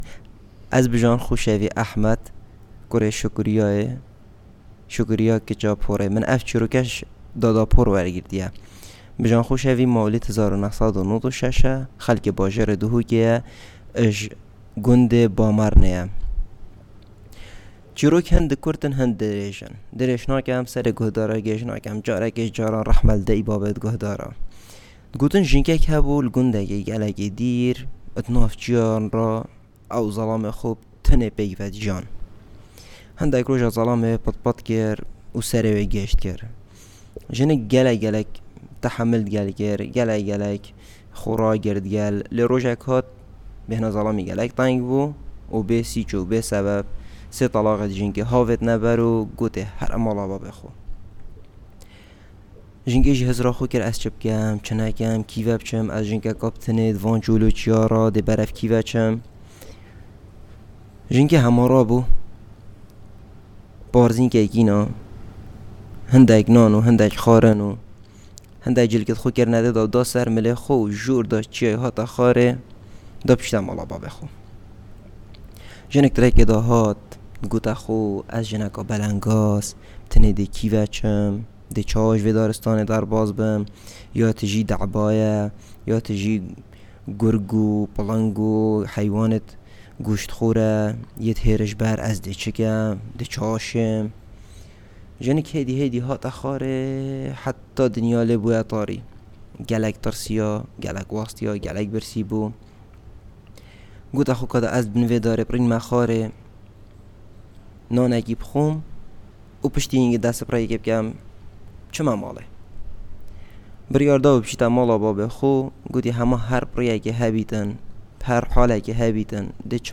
The Kurdish and Gorani Dialect Database